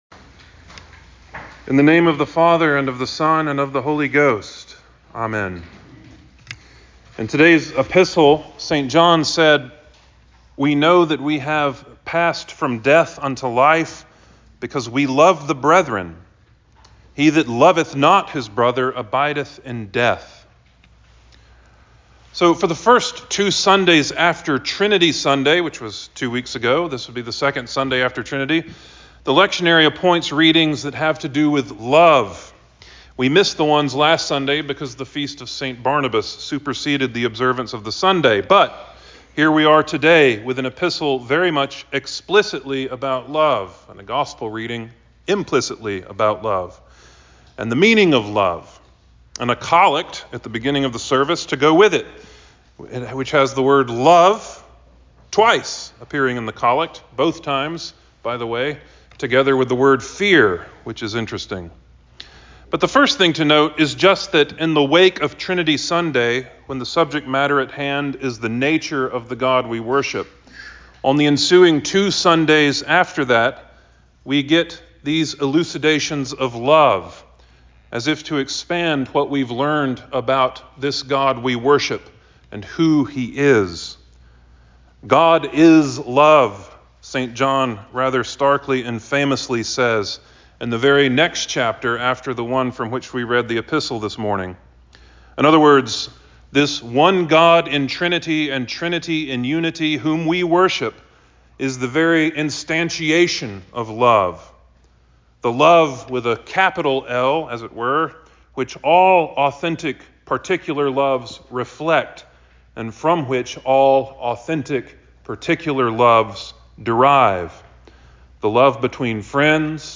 The Second Sunday After Trinity Sermon 06.18.23